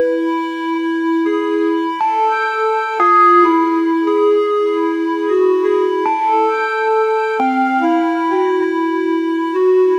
Royalty-free calm sound effects